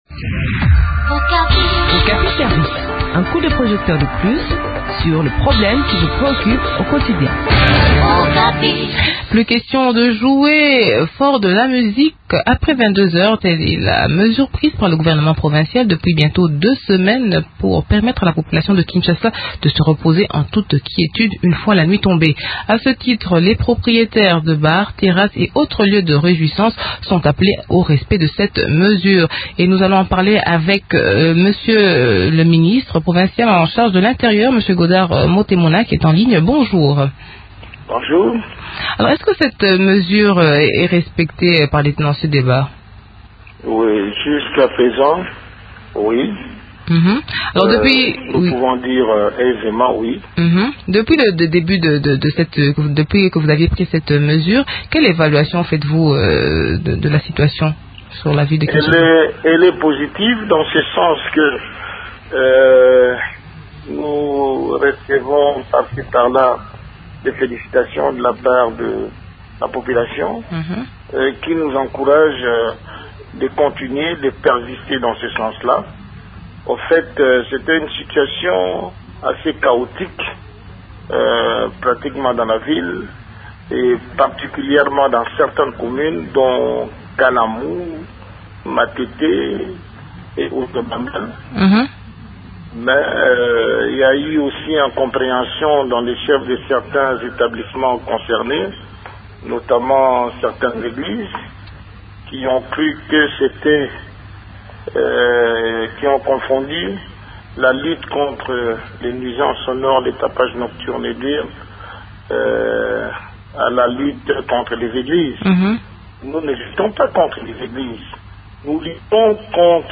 s’entretient sur ce qui a poussé les autorités à prendre cette mesure avec Godard Motemona, ministre provincial de l’Intérieur.